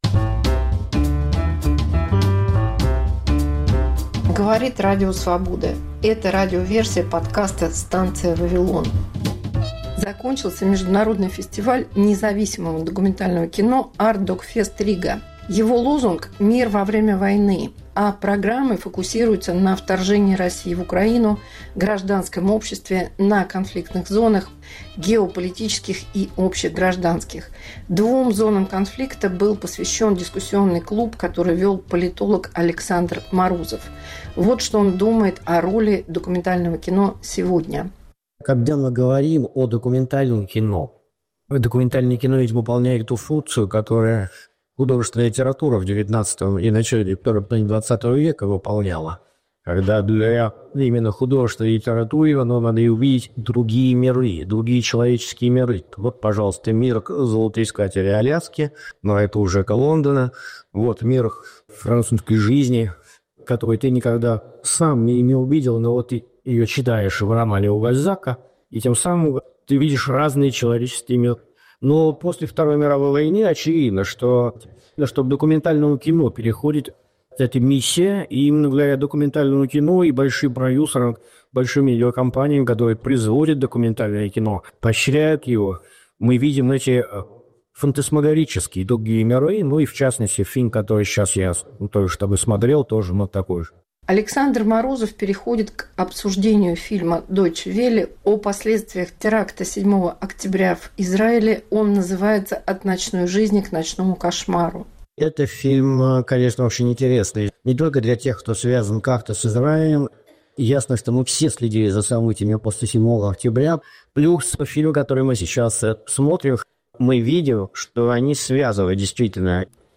Дискуссия на Artdocfest/Riga.